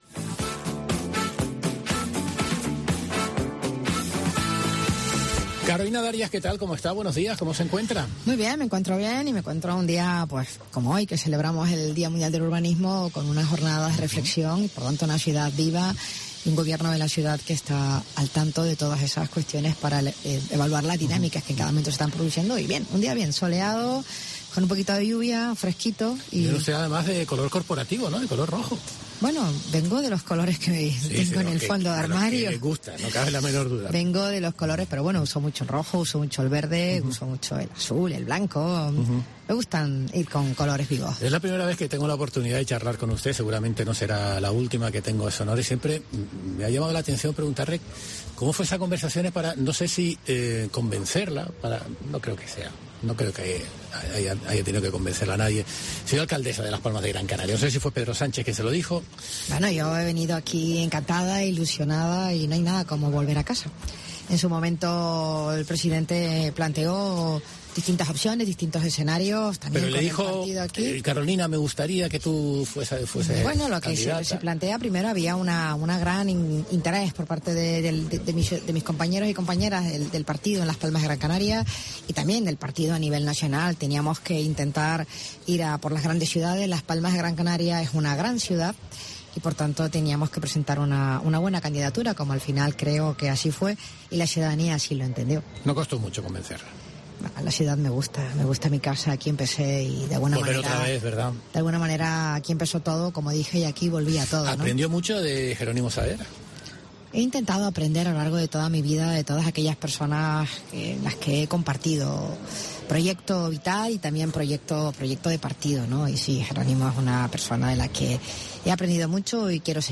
La alcaldesa de Las Palmas de Gran Canaria, Carolina Darias, en Herrera en COPE